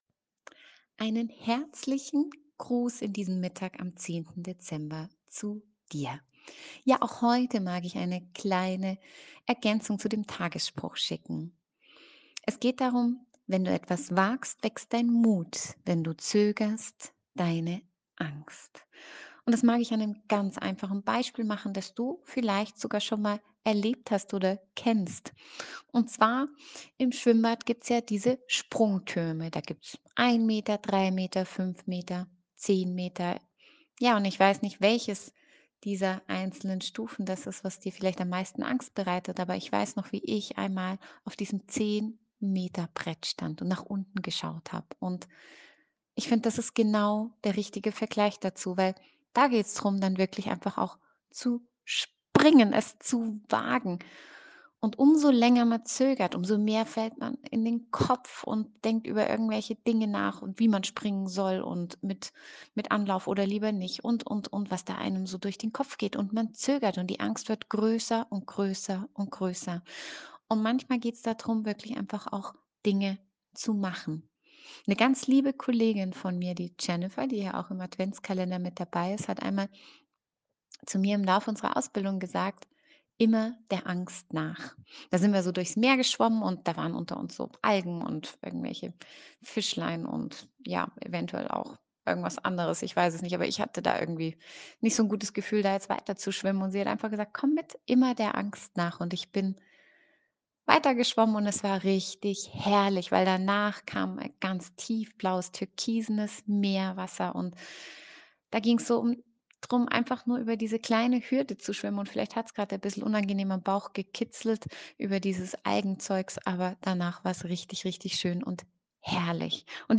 In diesem Jahr hab ich den ein oder anderen Spruch noch mit einer persönlichen Sprachnachricht ergänzt.